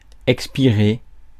Ääntäminen
IPA : /bɹiːð/